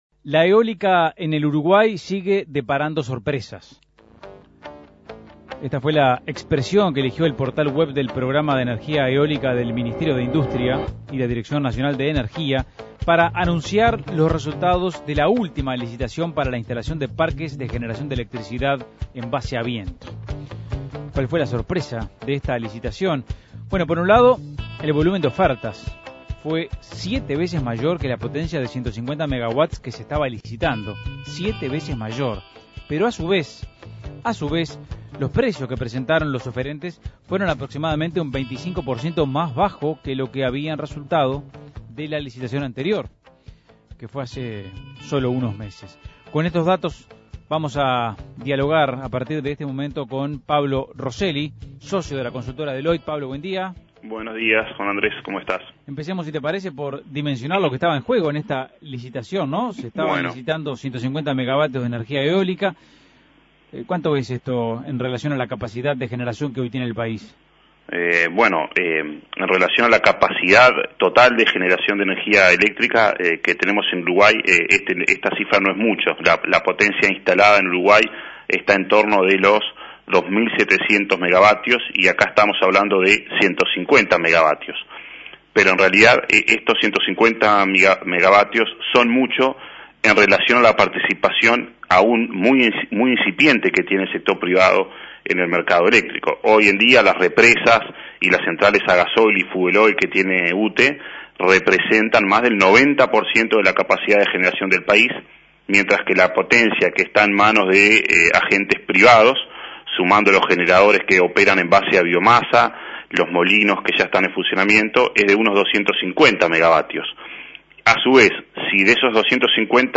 Análisis Económico ¿Qué conclusiones surgen de los resultados de la última licitación para la instalación de parques de energía eólica?